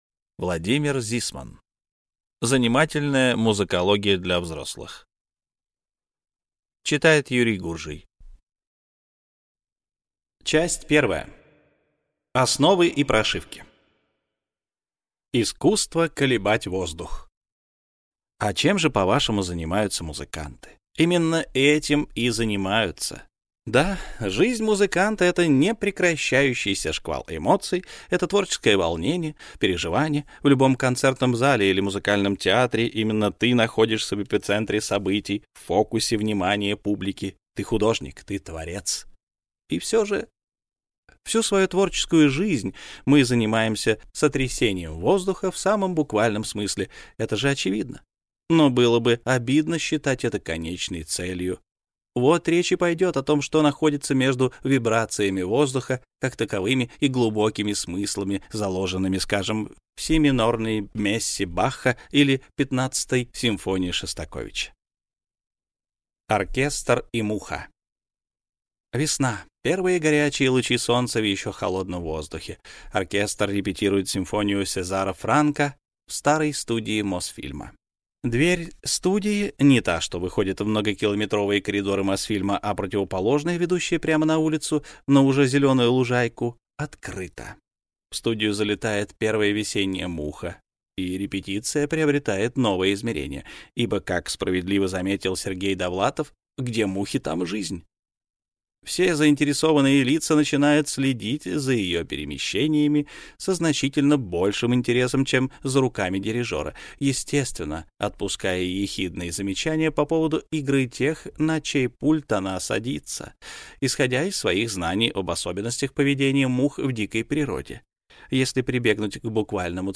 Аудиокнига Занимательная музыкология для взрослых | Библиотека аудиокниг